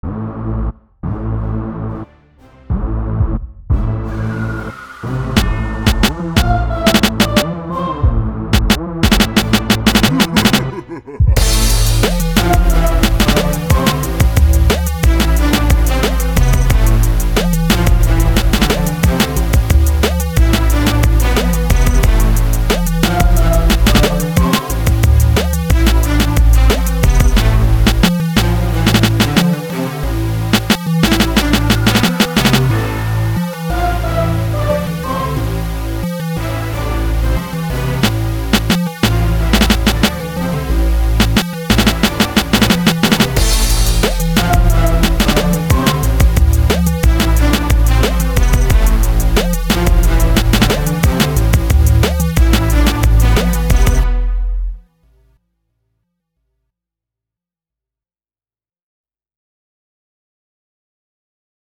Скачать Минус
Стиль: Progressive